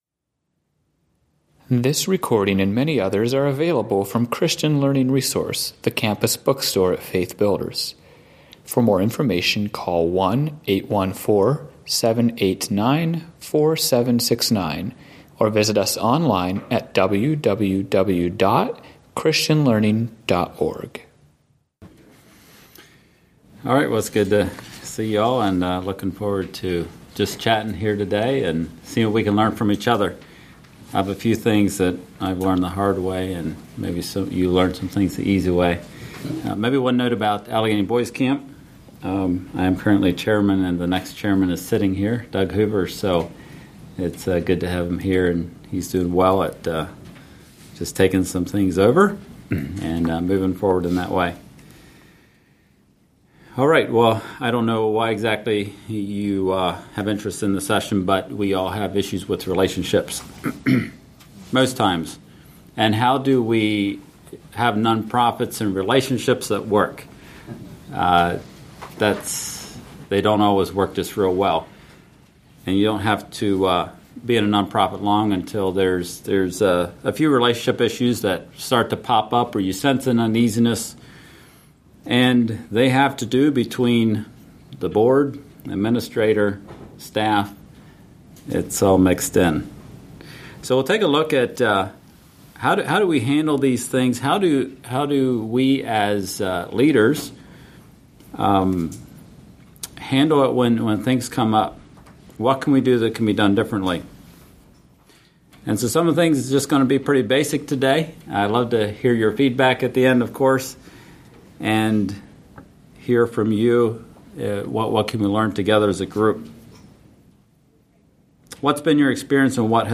Home » Lectures » Developing Productive Relationships in Nonprofits